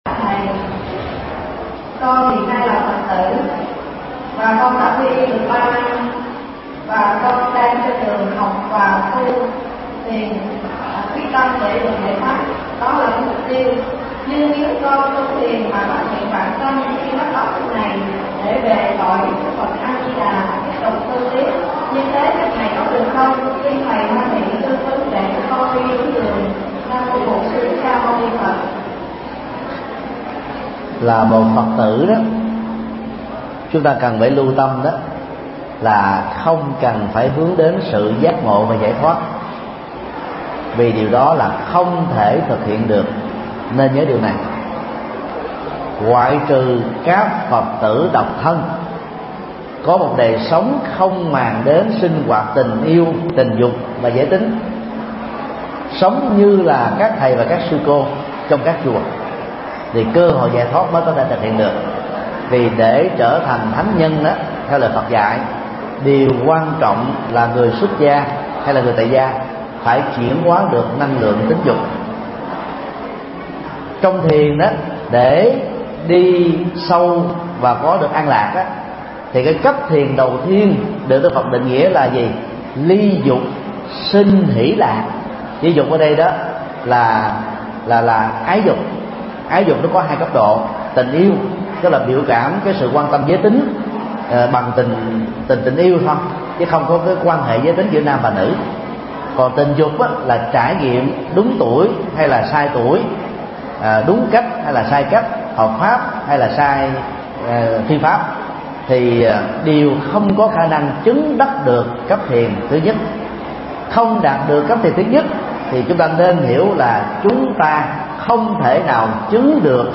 Vấn đáp: Phương pháp tu tập thiền định và tịnh độ – Thầy Thích Nhật Từ